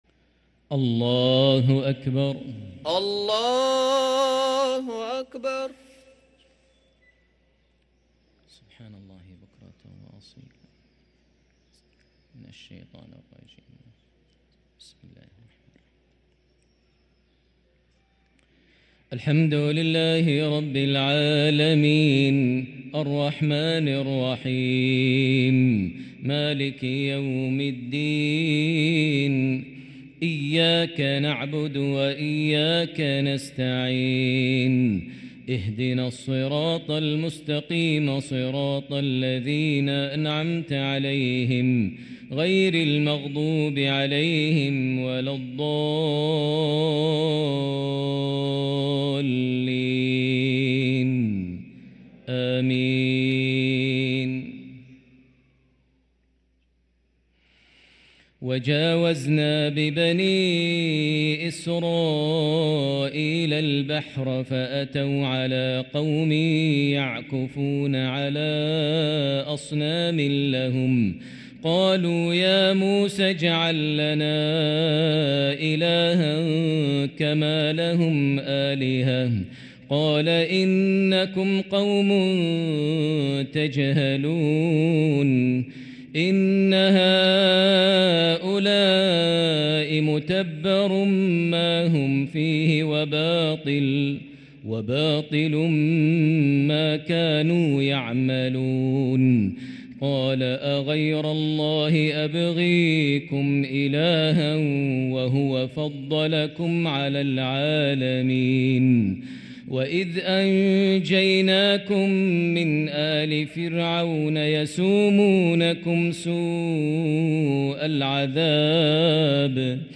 صلاة العشاء للقارئ ماهر المعيقلي 29 جمادي الأول 1445 هـ
تِلَاوَات الْحَرَمَيْن .